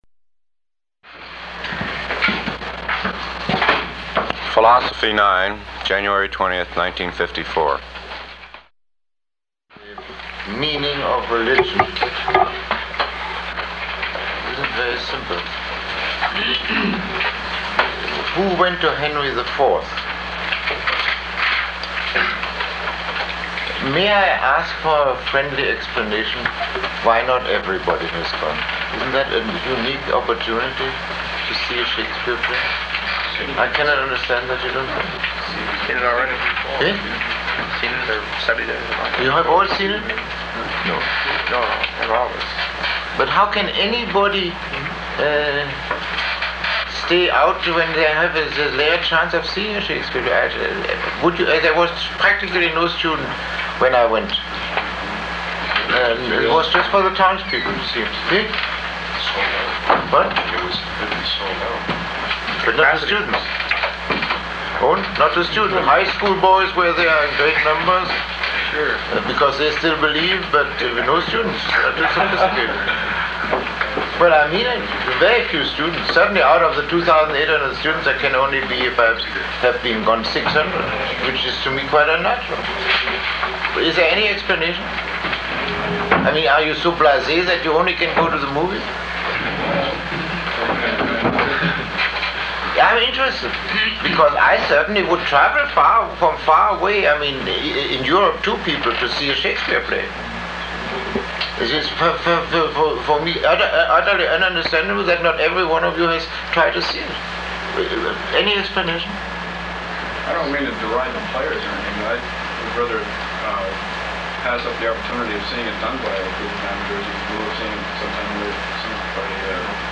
Lecture 23